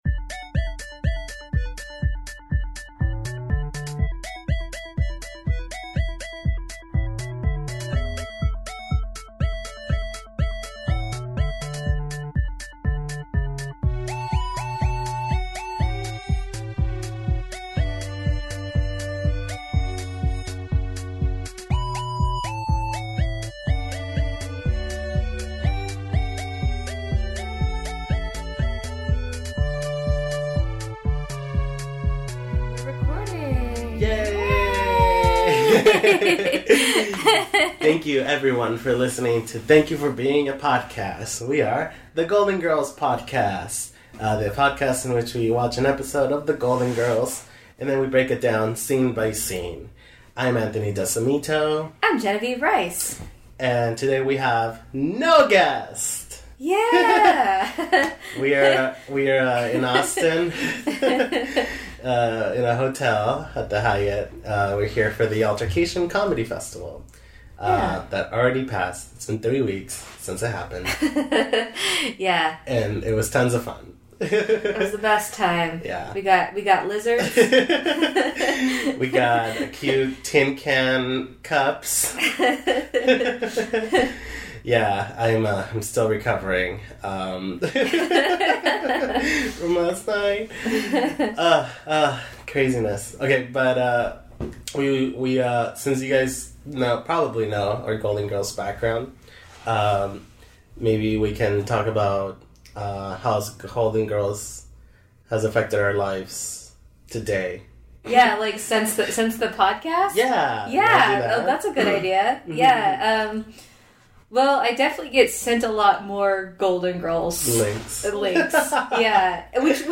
at the Hyatt Hotel in Austin, Texas!